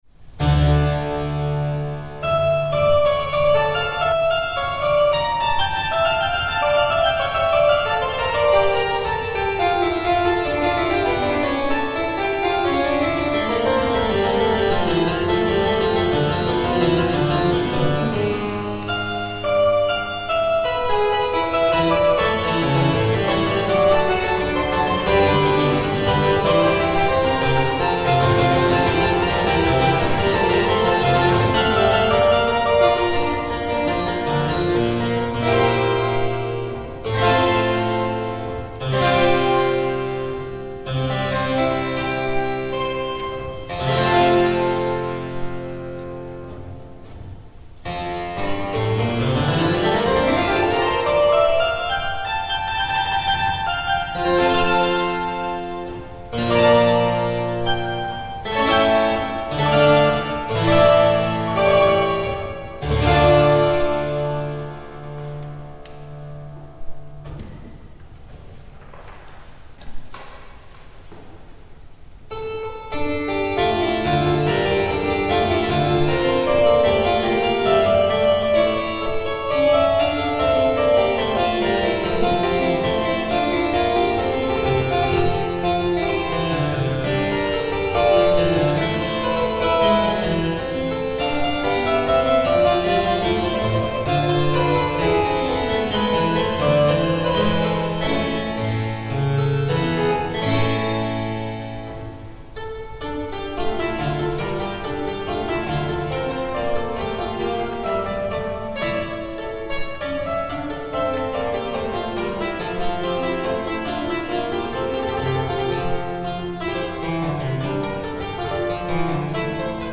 der heutige Tagesimpuls ist nonverbal, allein die Musik darf sprechen.
aufgenommen in St. Josef-Köpenick
Cembalosuite-dmoll.mp3